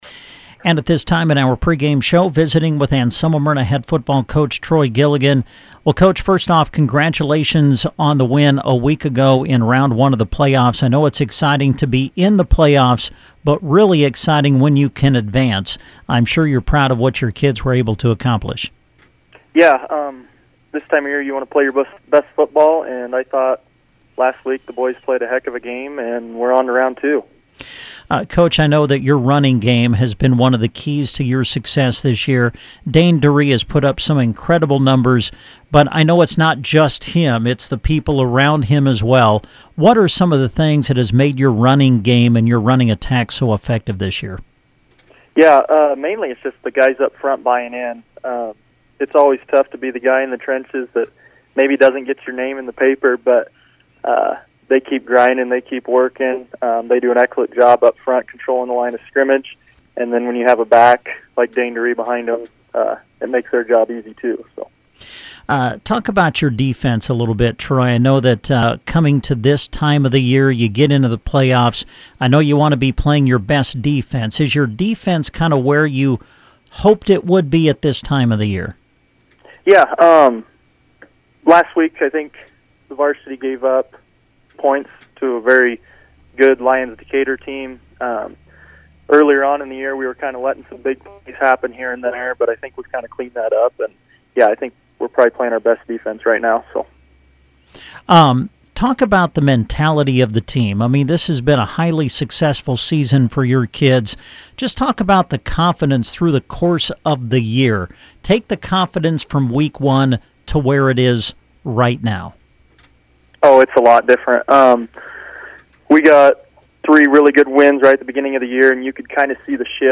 INTERVIEW WITH COACH